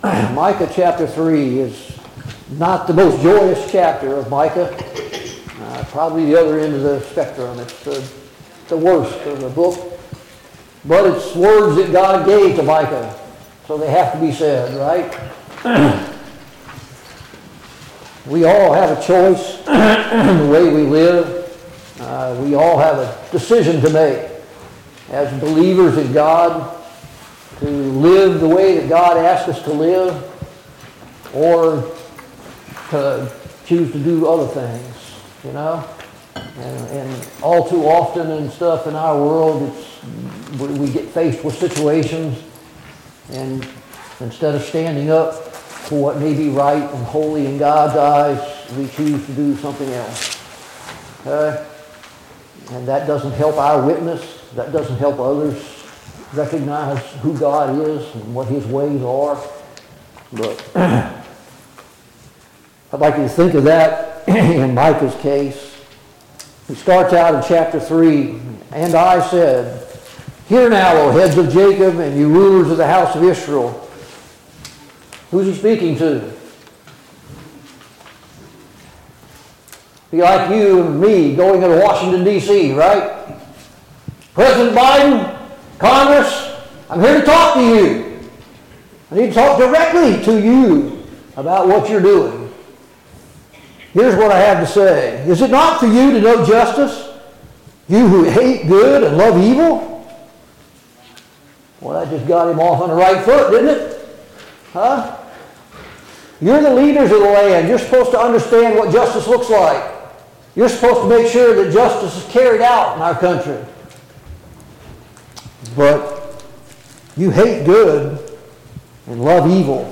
Study on the Minor Prophet Passage: Micah 3 Service Type: Sunday Morning Bible Class « 11.